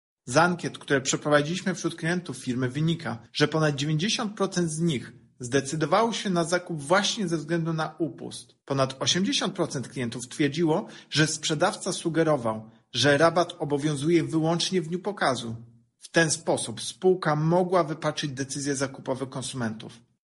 To nieuczciwa praktyka rynkowa – mówi prezes Urzędu Ochrony Konkurencji i Konsumenta Tomasz Chróstny: